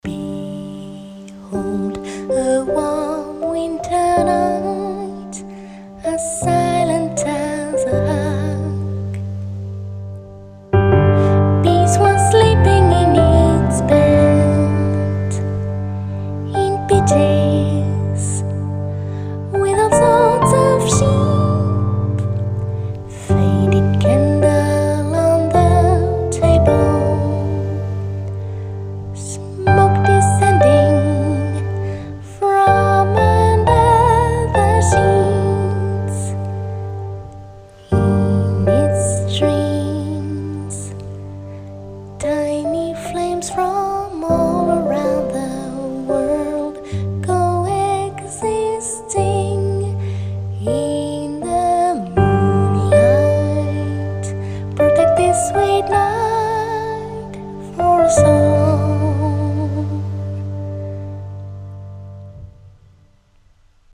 vocal arrangement